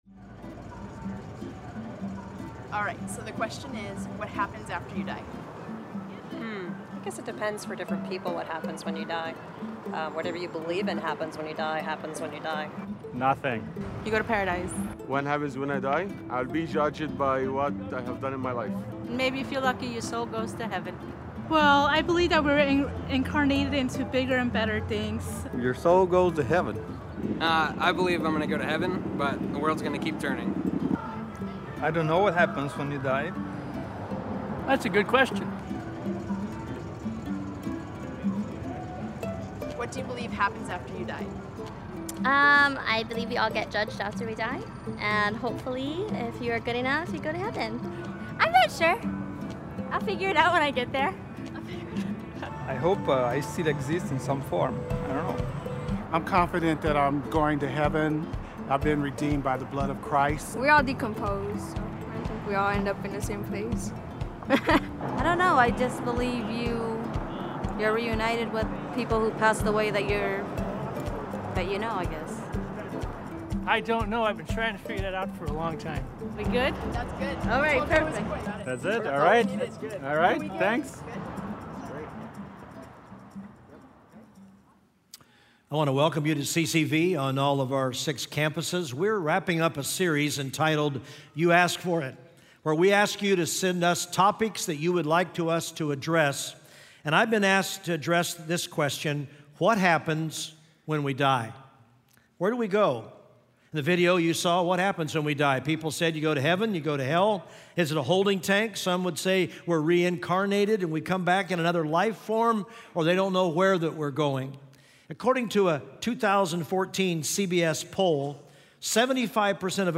Message Only Full Service What happens when we die?